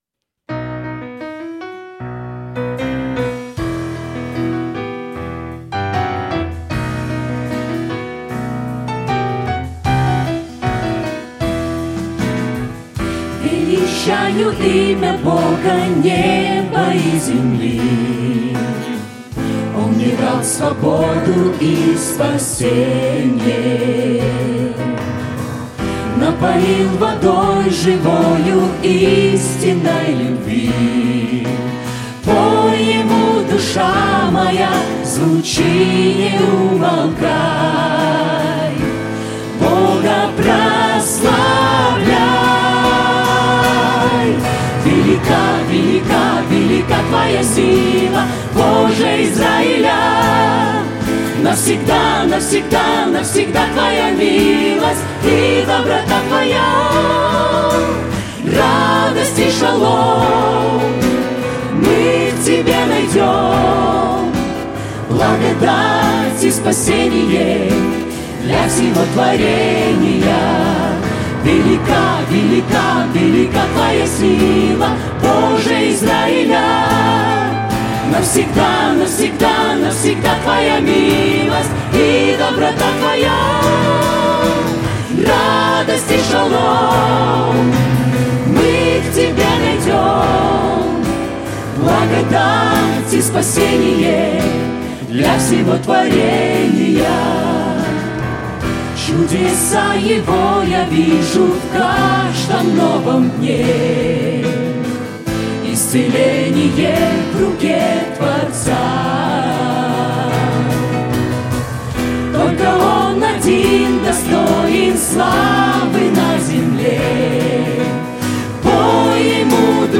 гитара
ударные
клавиши, вокал
вокал